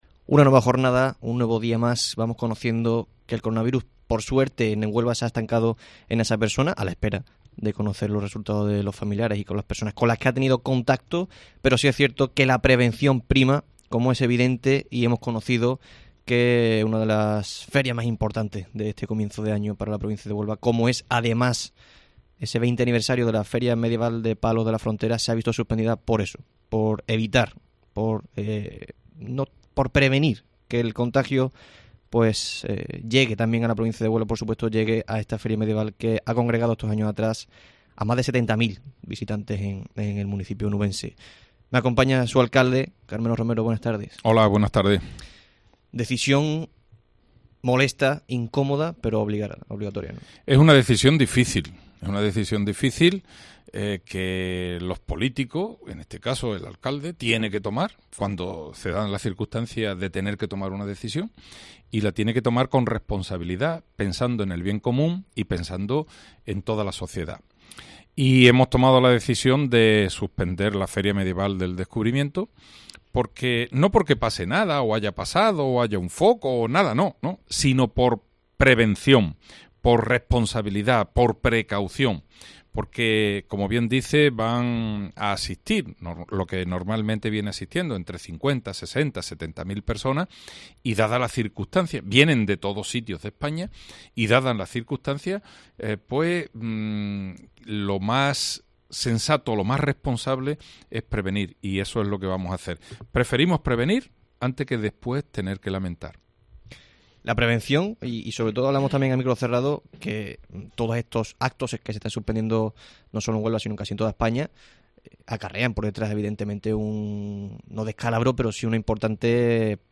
En el tiempo local de Herrera en COPE, el alcalde de Palos de la Fra., Carmelo Romero, explica los motivos por los que se ha suspendido la Feria Medieval.